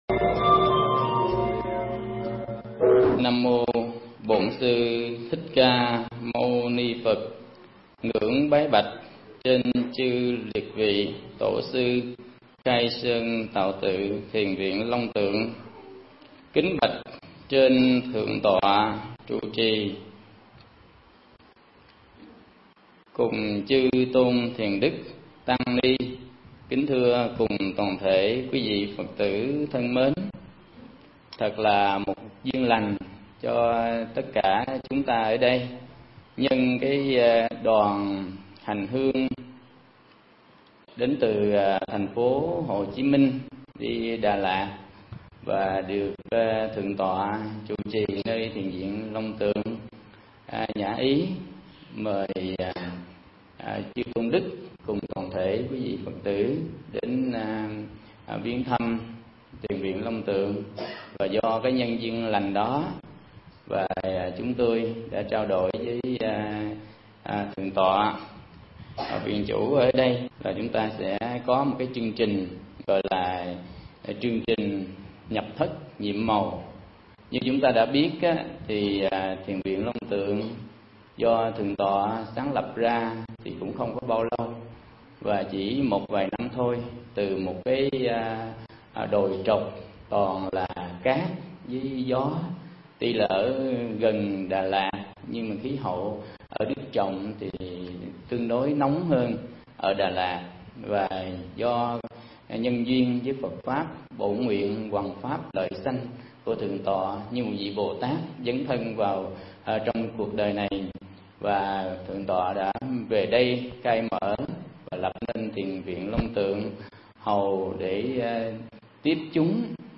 Mp3 Thuyết Pháp Nhập Thất Nhiệm Mầu ( ý nghĩa )
giảng tại Thiền Viện Long Tượng – Lâm đồng